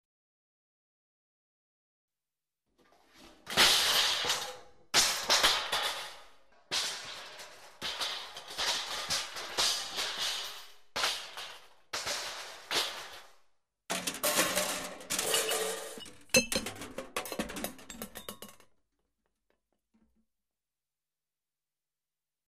Звуки грохота, падений
Звук для сцены: грохот за кадром, кто-то роняет что-то, возможно поднос